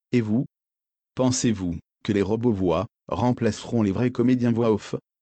La même voix robot en 2019 (Le visuel a bcp changé, je vous laisse juger du reste… )
Robot-voix-2019.mp3